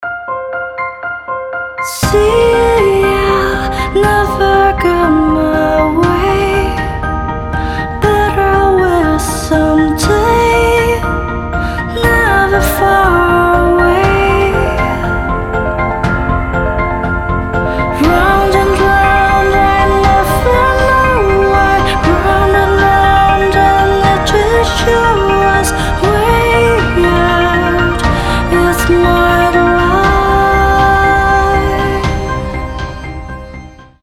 медленные
корейские
нежные
красивый вокал